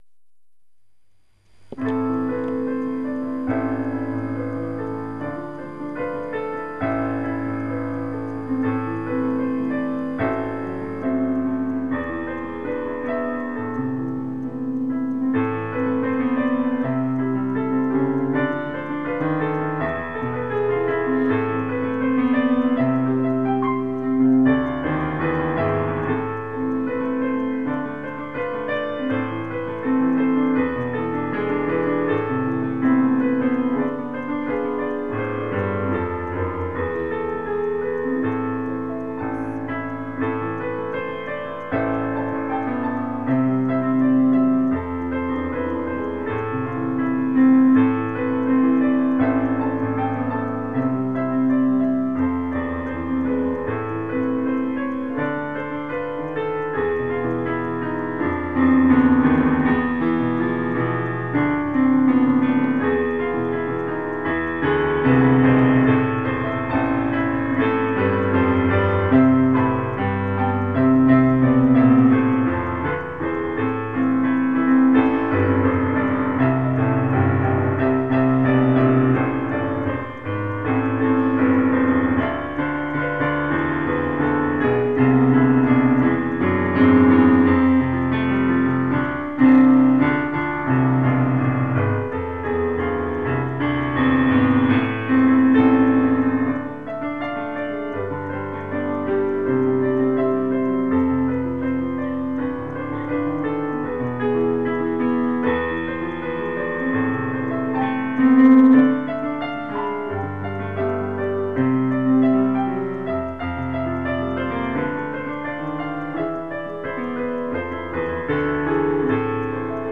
יצירה מקורית
יש שם רצף של תווים (שחוזר על עצמו כמה פעמים) שמזכיר את הלחן של המילים "ולפאתי מזרח" כפי שהולחנו בהמנון.
(אני לא מהמבינים במוזיקה אבל היו שם מקומות שהנגינה לא היתה "נקייה", כמו קולות שלא מסתנכרנים בהרמוניה. לתשומת לבך).